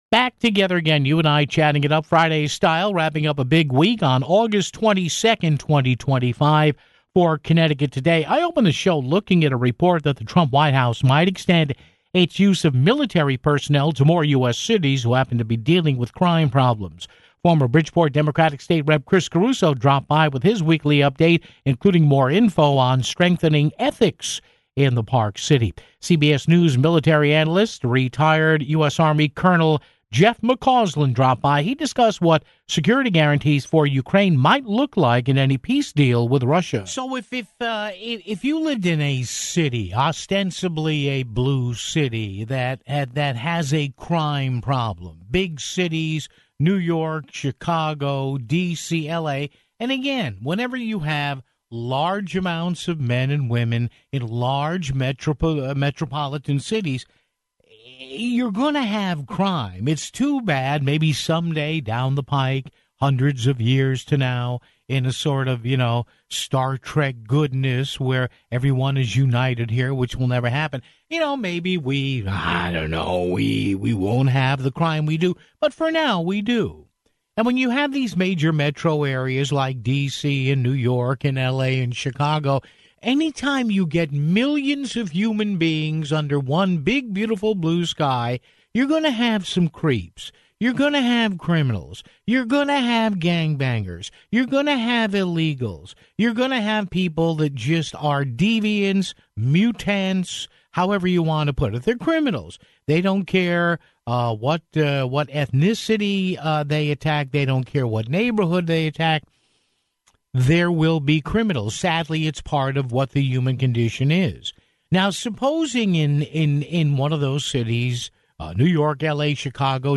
Former Bridgeport Democratic State Rep. Chris Caruso dropped by with his weekly update, including the latest news on strengthening government ethics in Bridgeport (14:54).